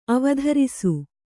♪ avadharisu